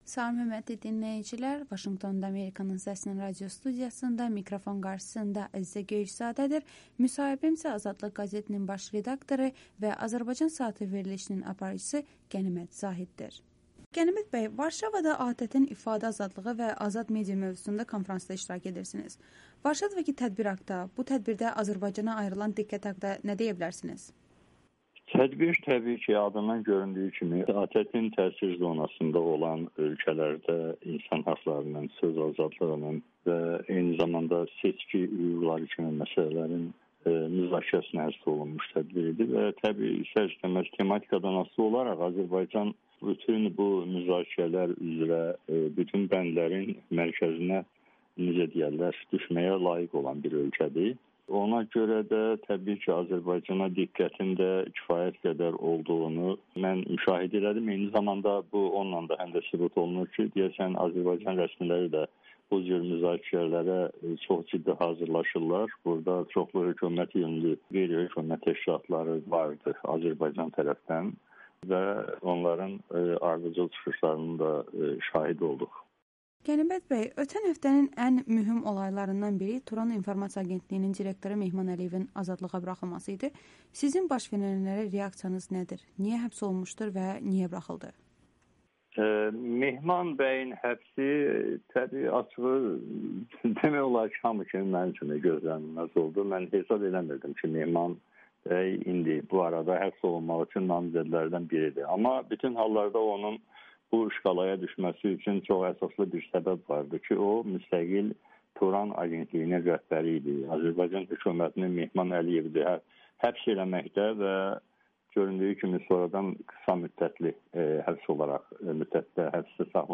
Müsahibələr